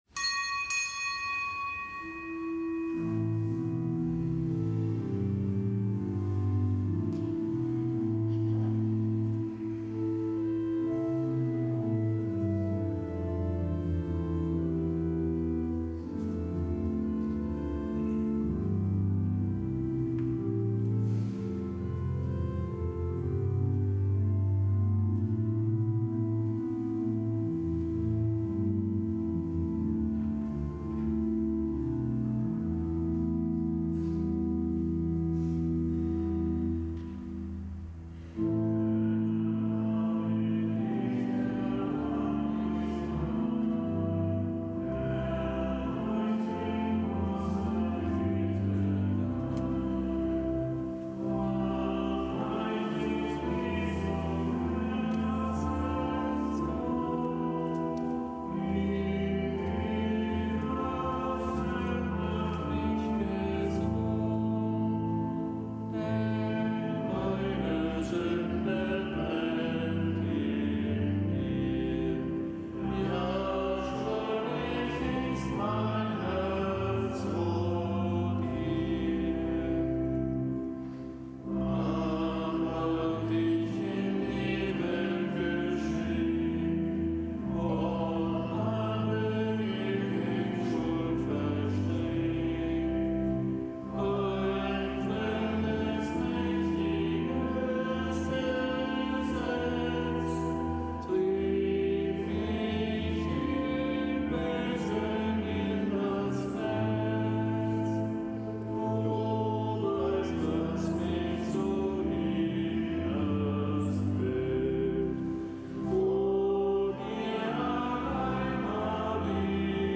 Kapitelsmesse aus dem Kölner Dom am Freitag der dritten Fastenwoche. Nichtgebotener Gedenktag der Heiligen Mathilde. Zelebrant: Weihbischof Dominikus Schwaderlapp.